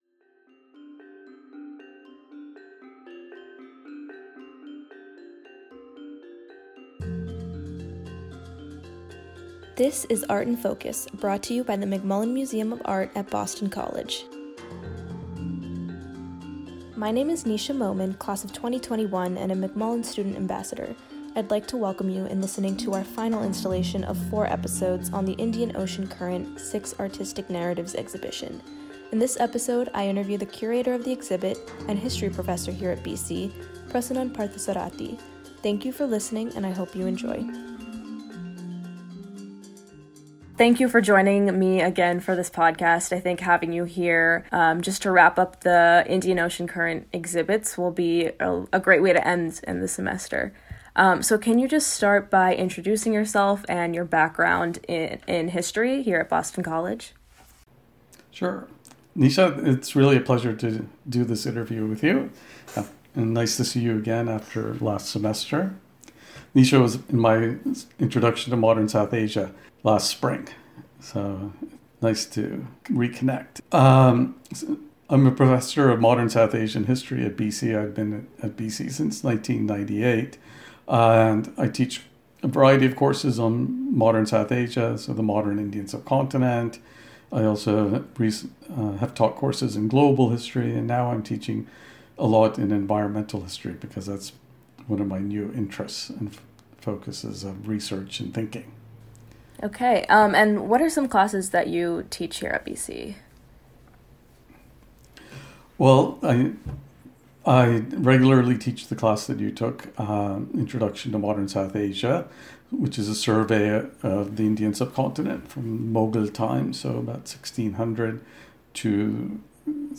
The McMullen Student Ambassadors are pleased to present Art in Focus, featuring an informal discussion between professors from various academic departments at Boston College.